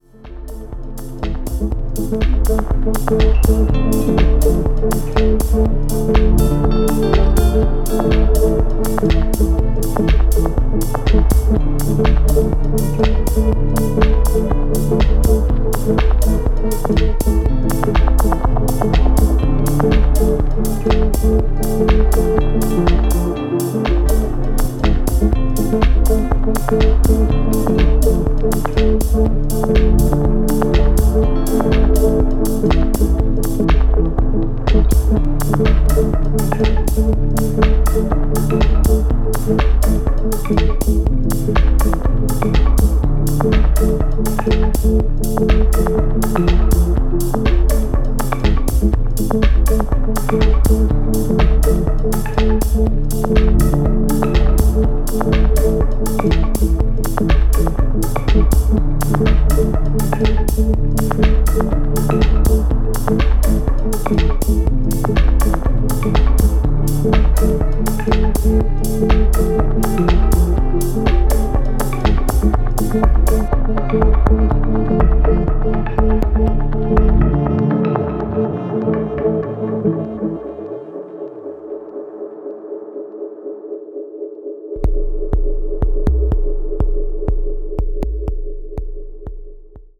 メランコリックなギターが美しい
とことん、渋い一枚。